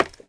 Minecraft Version Minecraft Version 25w18a Latest Release | Latest Snapshot 25w18a / assets / minecraft / sounds / block / bamboo / step2.ogg Compare With Compare With Latest Release | Latest Snapshot